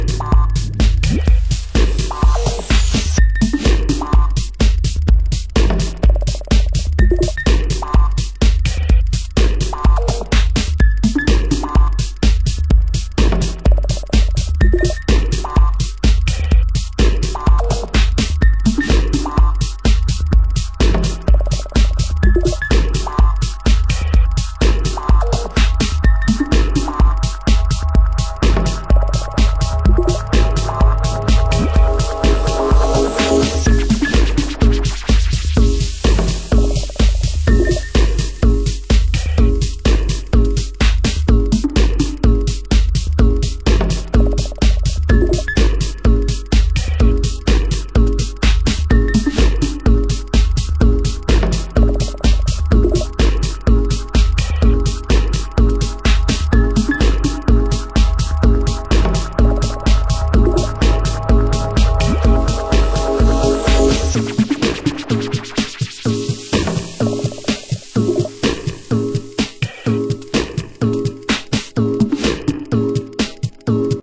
盤質：スレ小傷による少しチリノイズ有